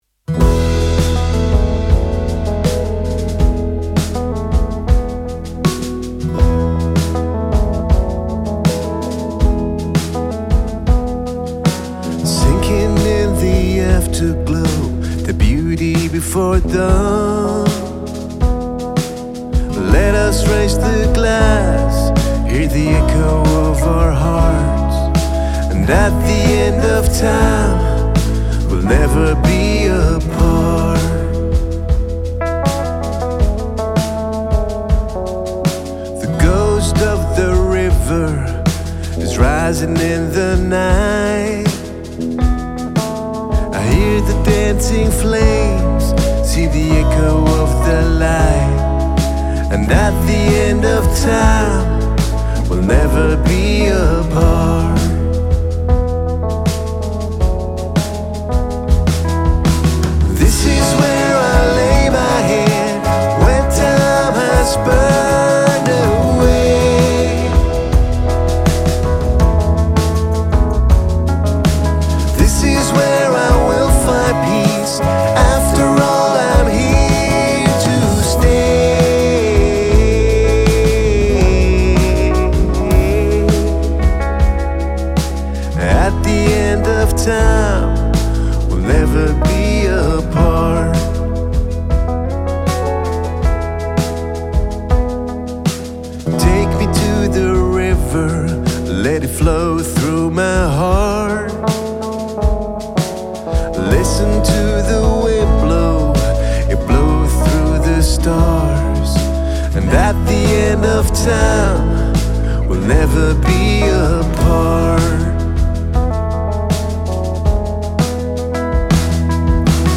Guitars, Pedal Steel Guitar, Mandolin,
Backing Vocals, Shaker&Tambourine
Lead Vocals
Bass Guitar
Drums
Keyboards